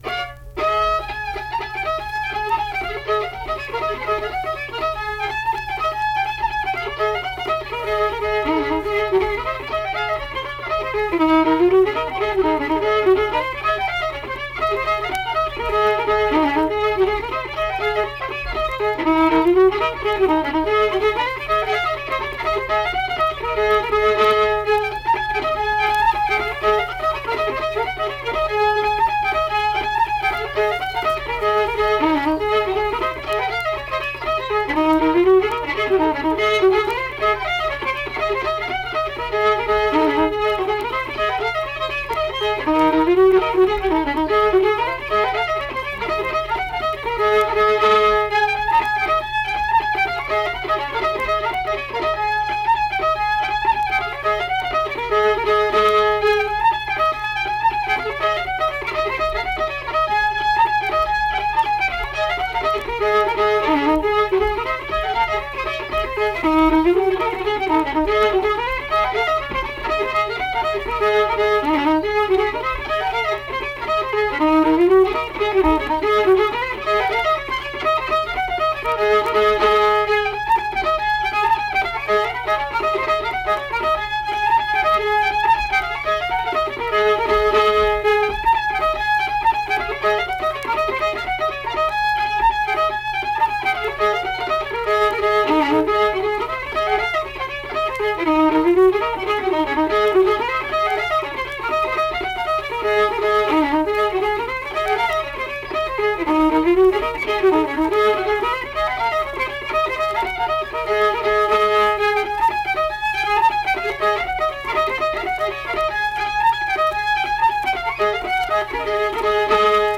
Unaccompanied fiddle music
Instrumental Music
Fiddle
Braxton County (W. Va.), Flatwoods (Braxton County, W. Va.)